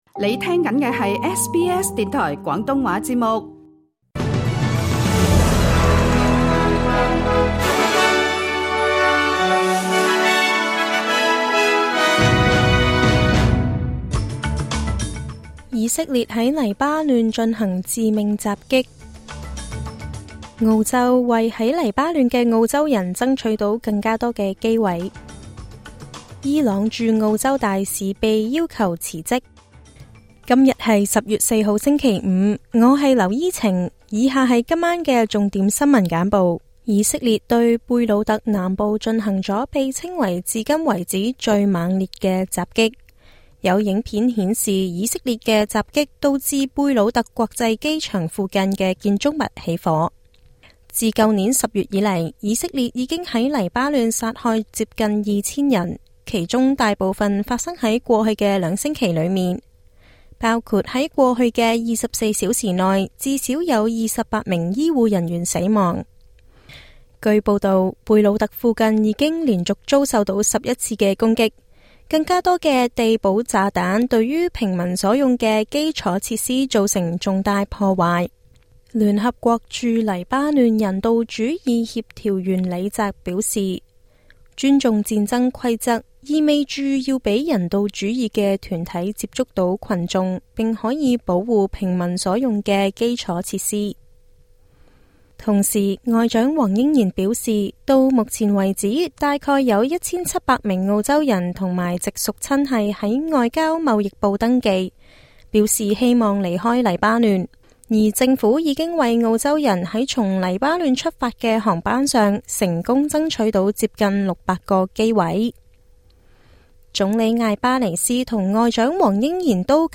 SBS 晚間新聞（2024年10月4日）
SBS 廣東話晚間新聞